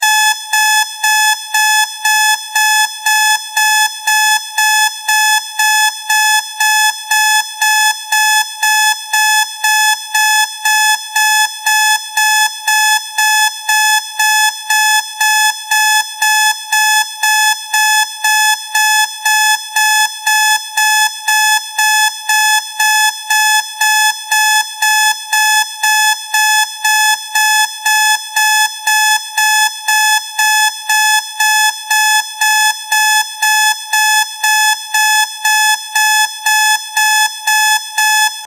警報音風の目覚まし時計アラーム音。
リバーブバージョン。